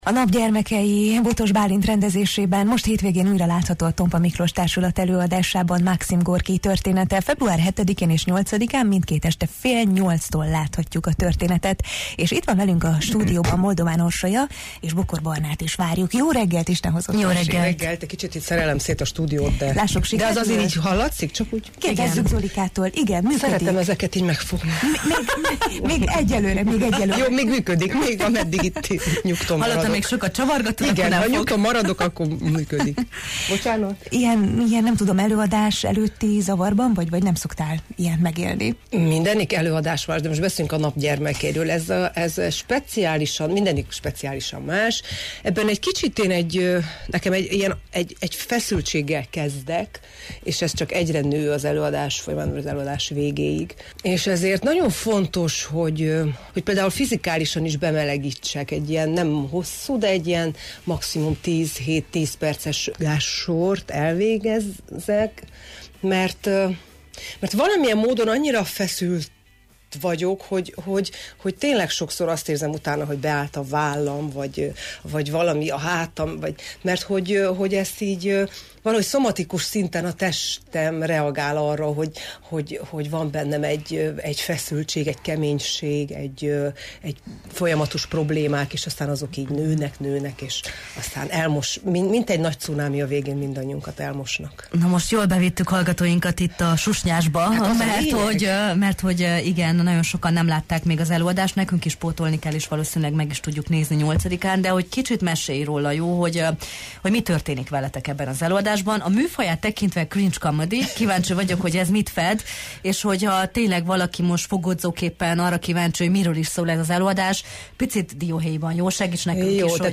vele beszélgettünk elitizmusról, jó szándékról, vakságról, és arról, mitől lesz egy több mint százéves darab zavarba ejtően mai.